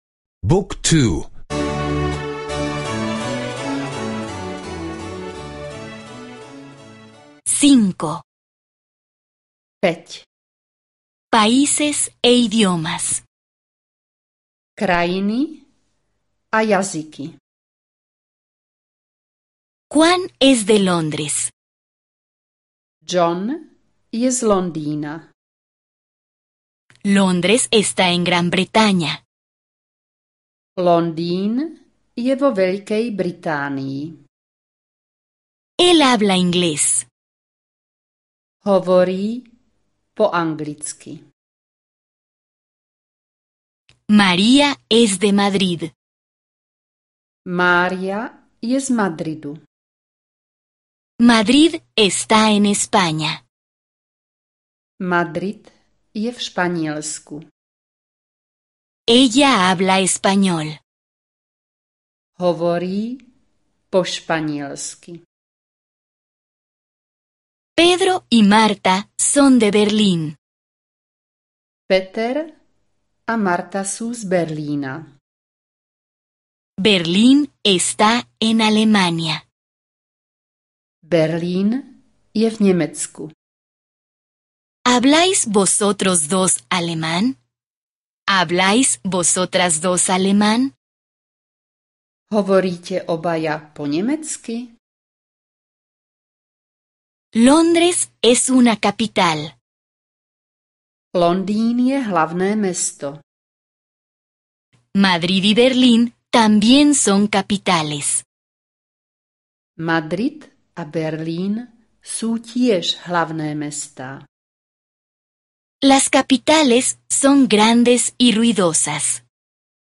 Curso de audio de eslovaco (escuchar en línea)